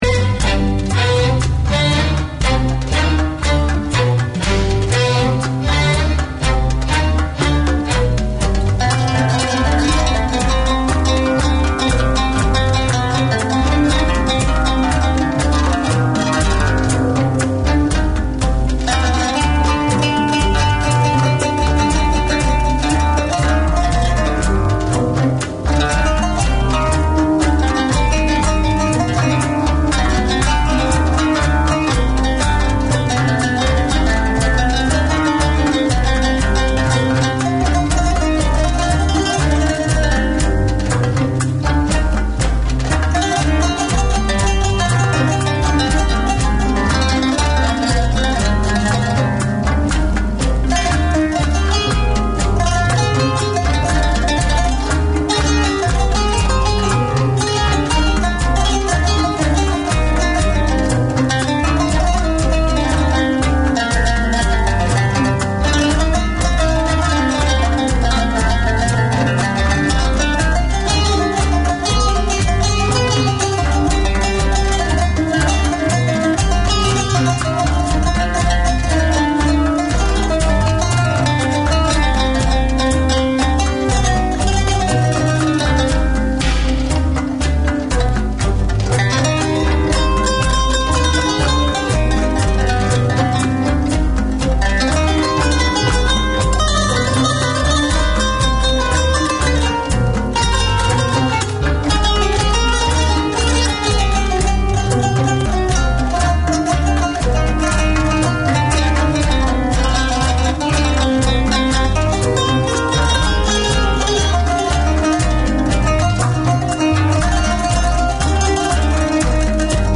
With every third show dedicated solely to Americana